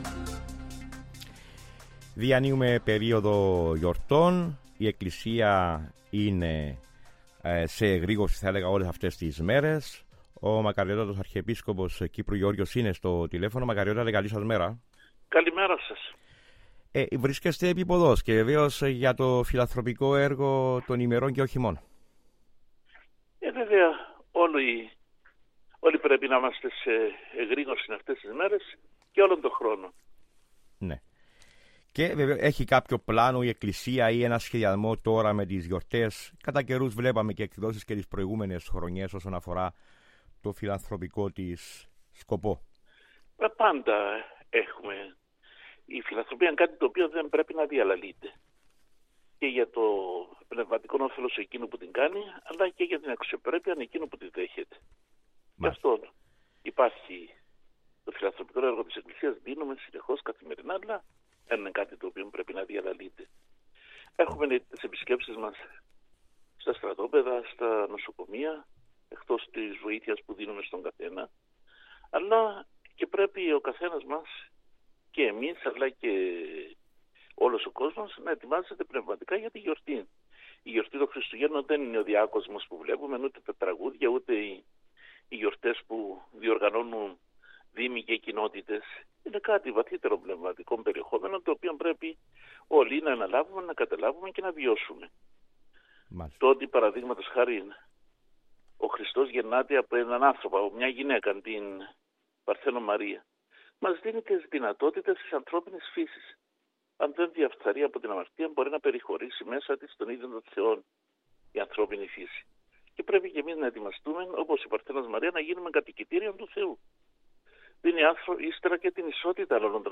Μιλώντας στο Πρωινό Δρομολόγιο (16.12.2024), είπε ότι ανάλογα με το αποτέλεσμα της δίκης και τα ευρηματα που θα εξαχθούν, είναι δυνατόν η εκκλησία να ξαναδει το θέμα.